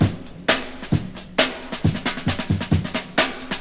• 133 Bpm HQ Drum Beat G Key.wav
Free breakbeat sample - kick tuned to the G note. Loudest frequency: 2110Hz
133-bpm-hq-drum-beat-g-key-LkI.wav